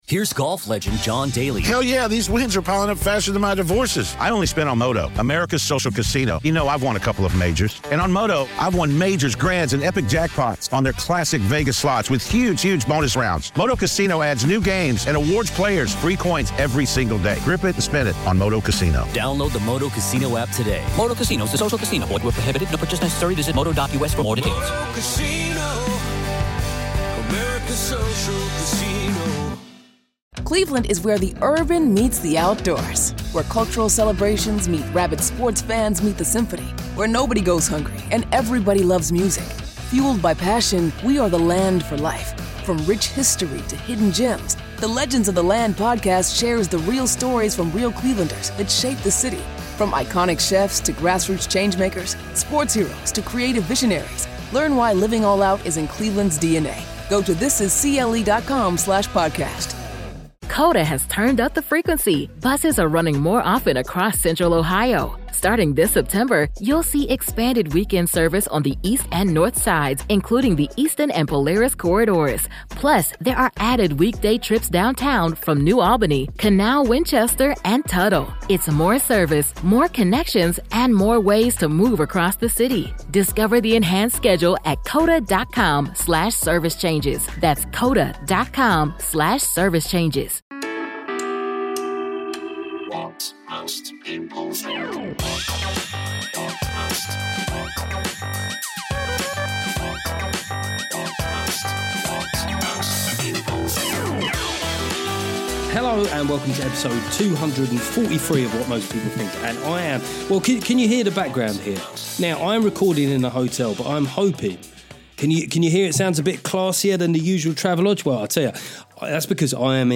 This week I climb into the Scottish hate crime bill cluster**** and how JK Rowling was able to call Humza's bluff. I also tentatively wonder if the Tory backlash has got a bit excessive. Additionally, there's another FREE audiobook chapter from THE BRITISH BLOKE DECODED.